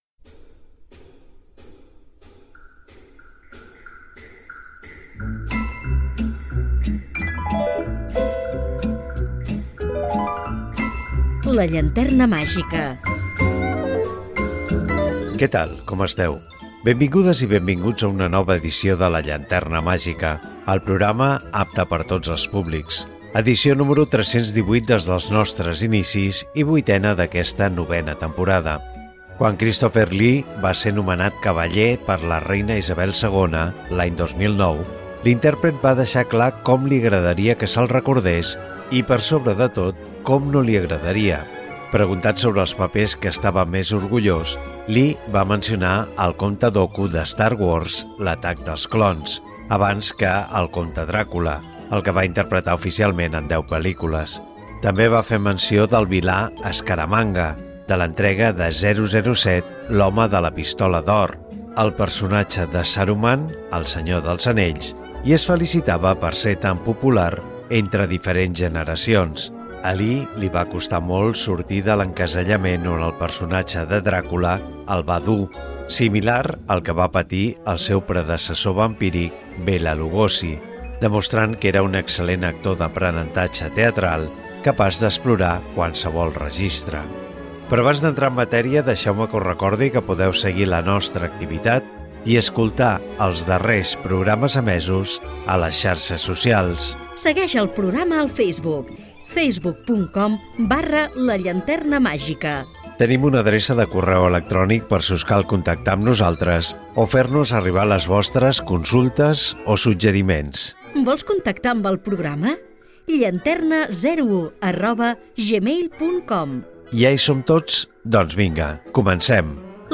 El seu objectiu és passar i fer passar una bona estona a tothom mentre parlem de cinema i escoltem música de pel·lícula.